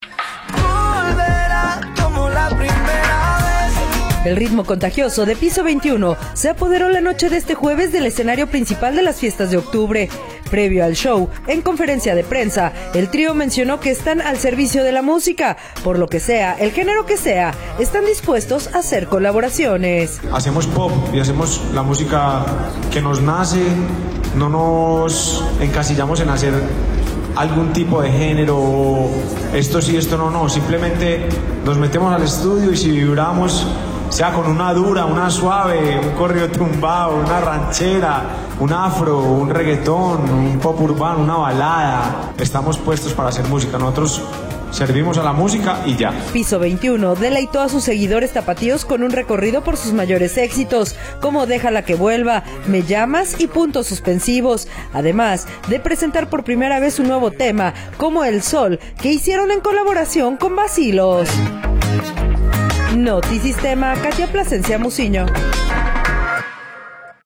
El ritmo contagioso de Piso 21 se apoderó la noche de este jueves del escenario principal de las Fiestas de Octubre.